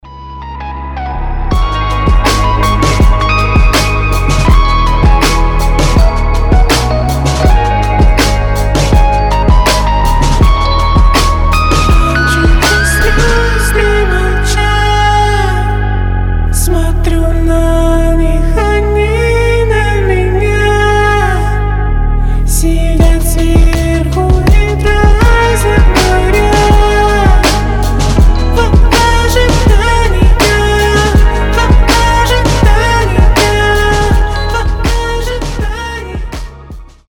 рэп
космические , атмосферные , мелодичные